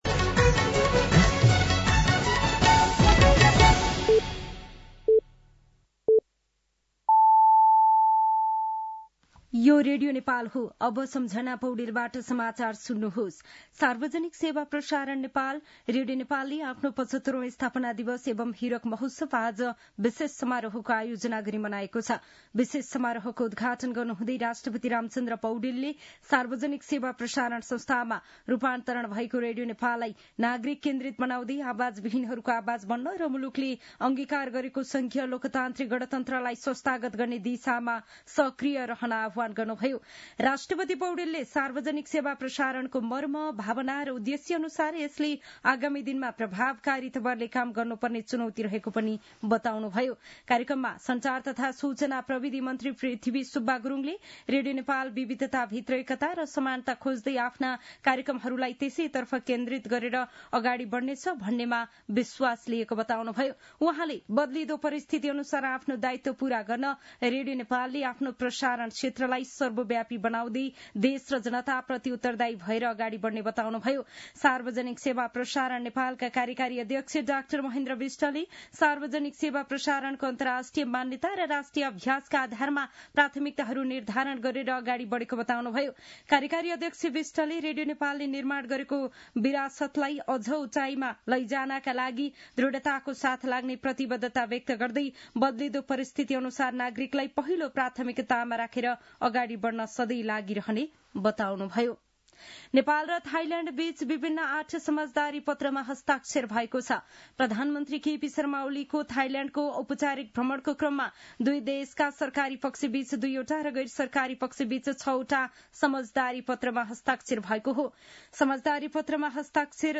साँझ ५ बजेको नेपाली समाचार : २० चैत , २०८१
5-pm-news.mp3